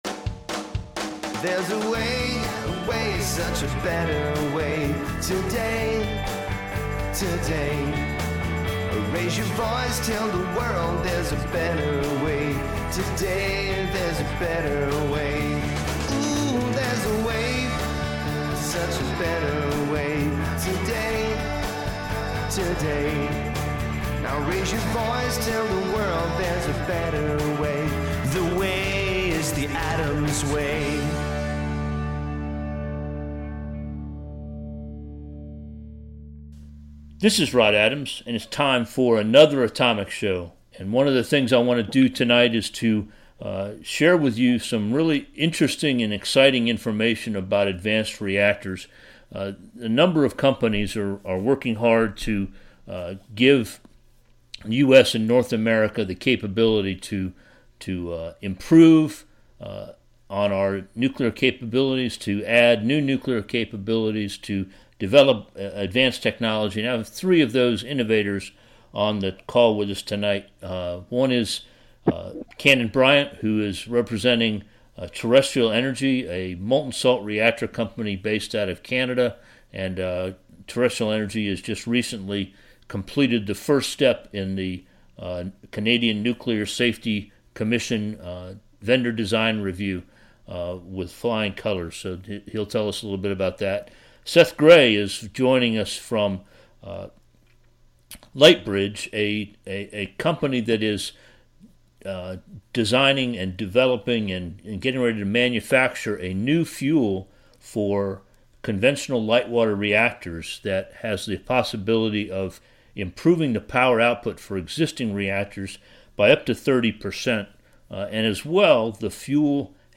Advanced nuclear development projects have made some significant steps during 2017. I met – via Skype – with representatives of three of the projects yesterday.